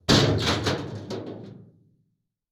255081e1ee Divergent / mods / Soundscape Overhaul / gamedata / sounds / ambient / soundscape / underground / under_7.ogg 65 KiB (Stored with Git LFS) Raw History